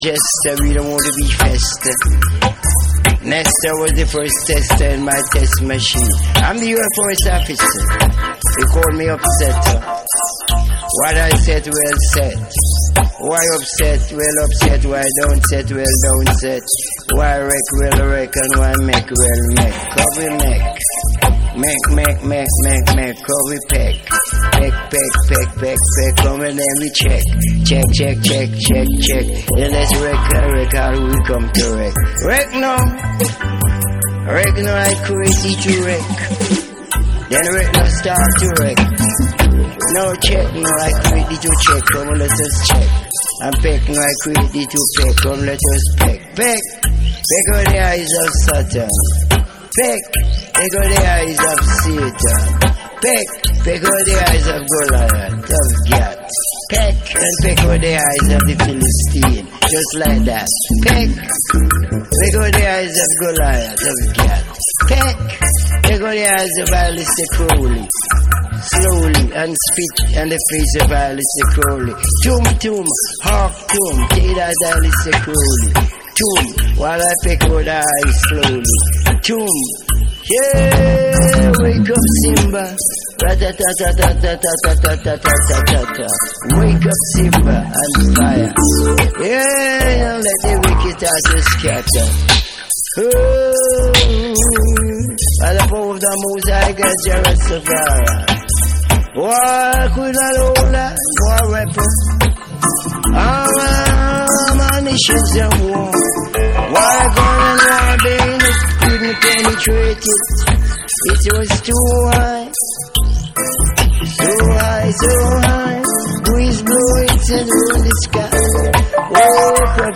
ジャングル〜ブレイクビーツ〜ダブが交差するBPM140ぐらいのリズミカル・ブレイク
REGGAE & DUB